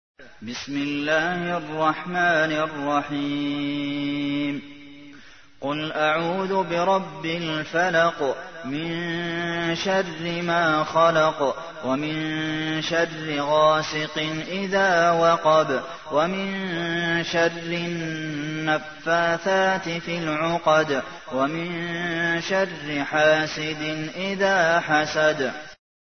تحميل : 113. سورة الفلق / القارئ عبد المحسن قاسم / القرآن الكريم / موقع يا حسين